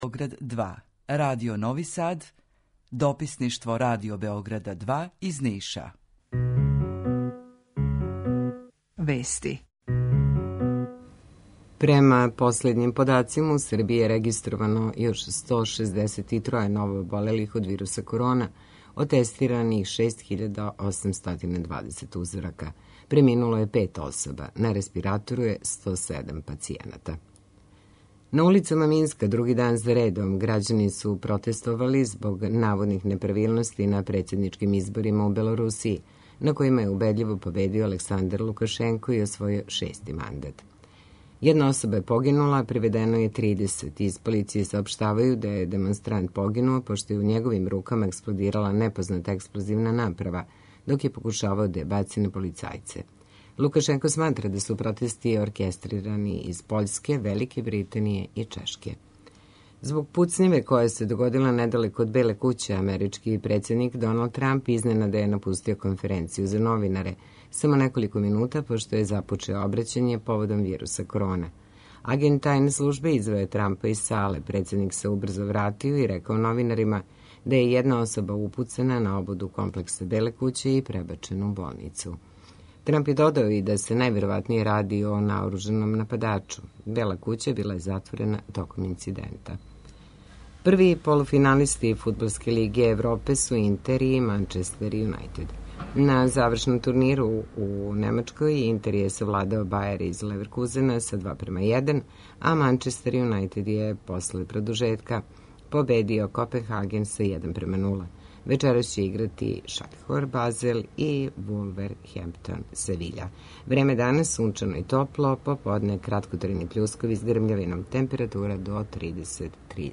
У два сата, ту је и добра музика, другачија у односу на остале радио-станице.